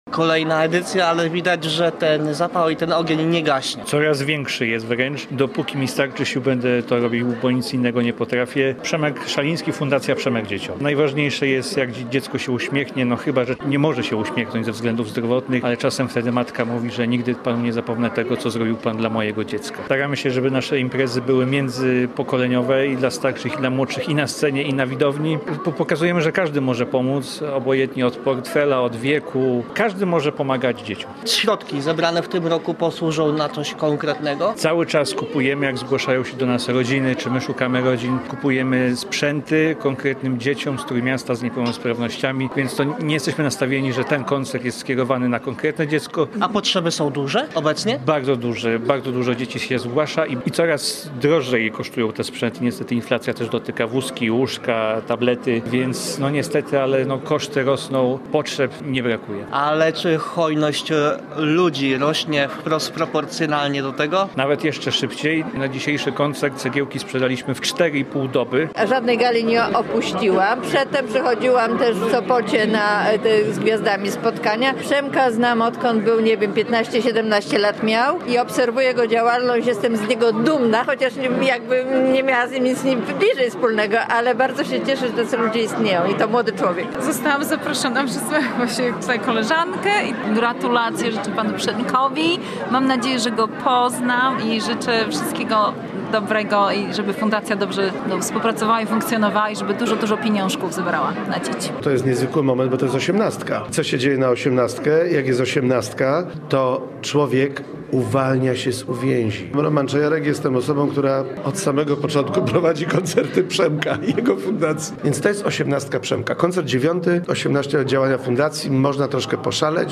Od samego początku wspiera dzieci borykające się z niepełnosprawnościami. W sobotę w Gdańsku odbył się dziewiąty charytatywny koncert gwiazd.
Posłuchaj materiału reportera: https